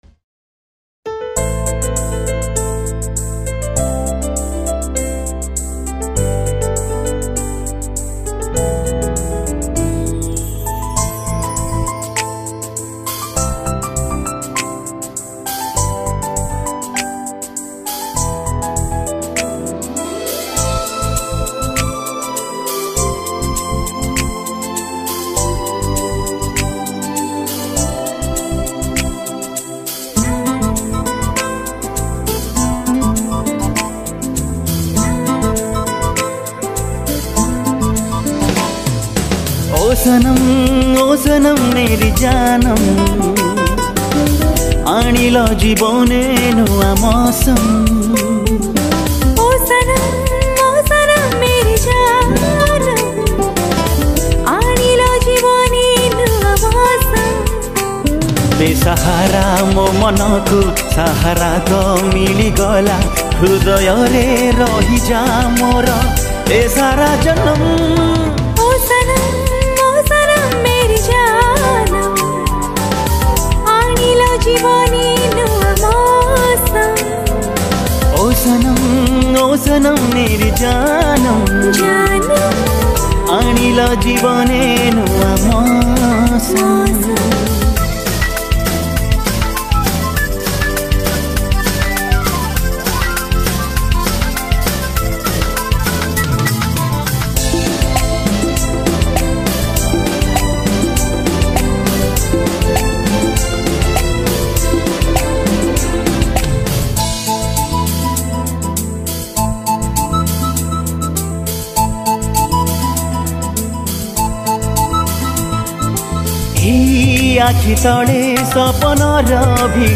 Odia Romantic Song